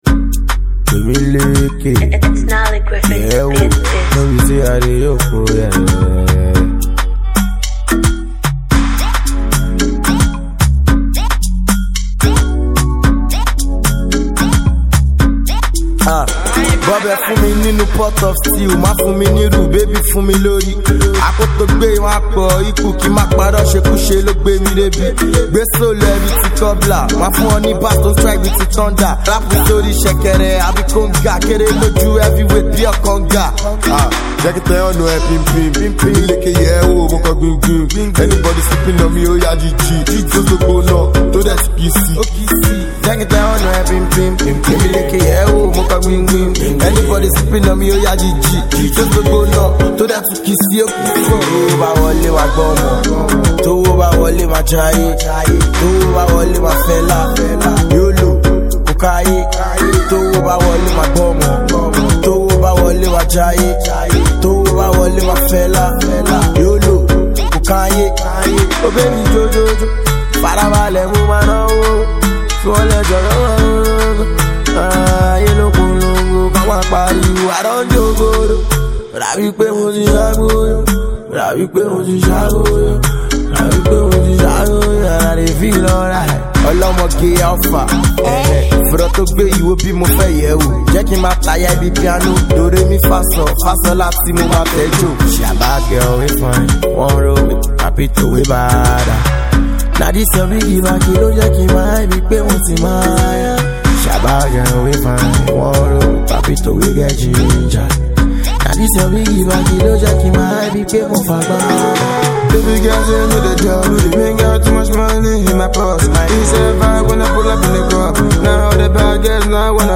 Afro danceable jam